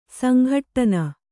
♪ sanghaṭṭana